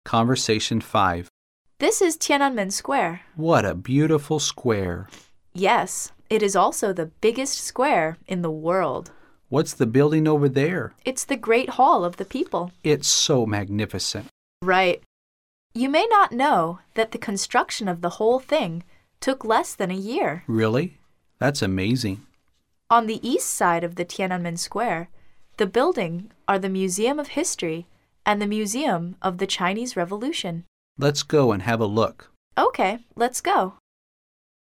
Conversation 5